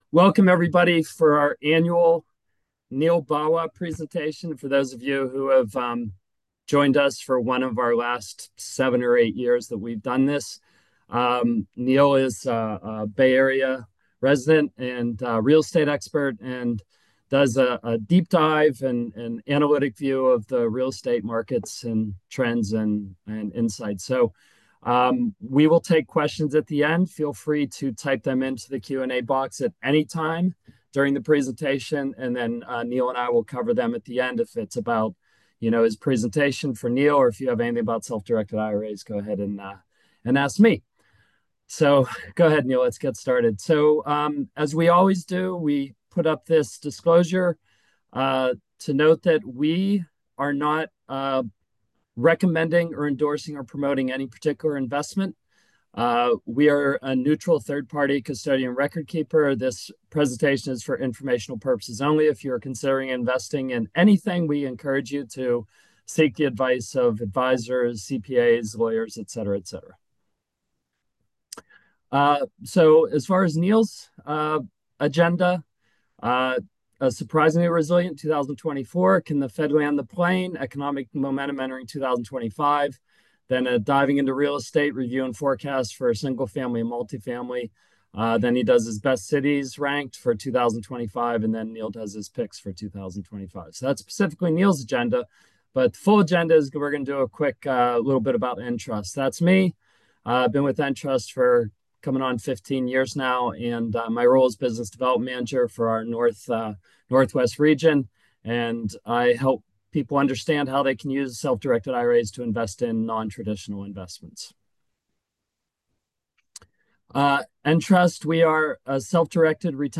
Webinar_February_2025_Audio.m4a